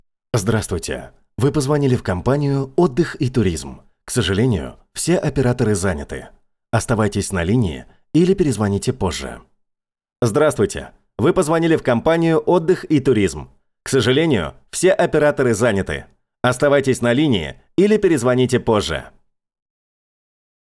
Автоответчик
Муж, Автоответчик/Молодой